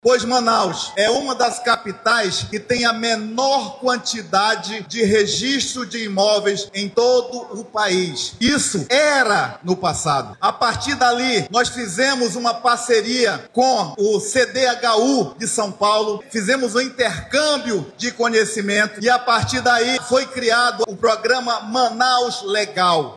O titular da Semhaf, Jesus Alves, lembrou que Manaus figurava entre as capitais com menor número de imóveis regularizados no país.
SONORA-1-JESUS-ALVES-.mp3